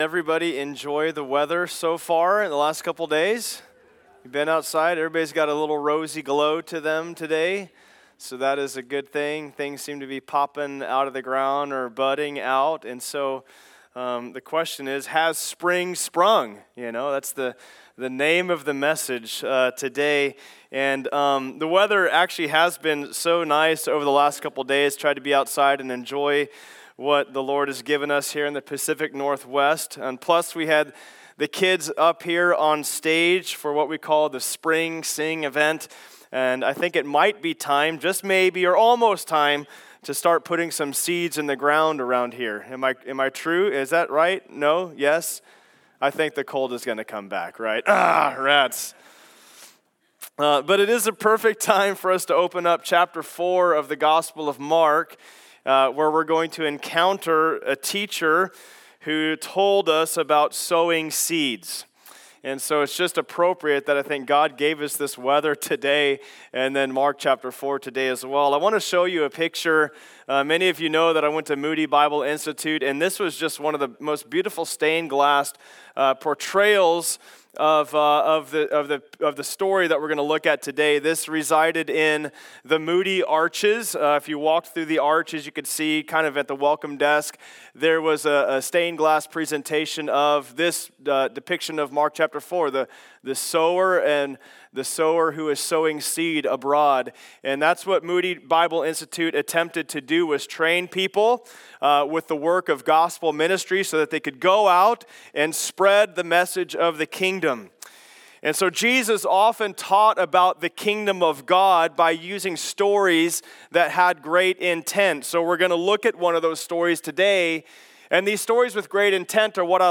Passage: Mark 4:1-20 Service Type: Sunday Service Download Files Notes « Gospel of Mark: Jesus Is…